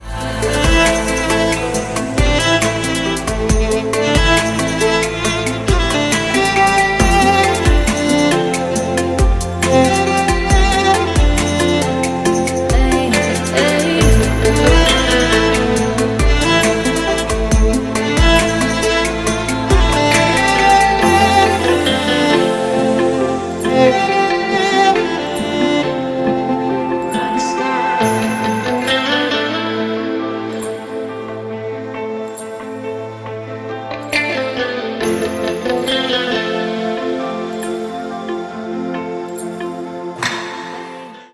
• Качество: 320, Stereo
deep house
скрипка
инструментальные
электрогитара
восточные
Melodic house
Мелодичное и ритмичное звучание электрогитары и скрипки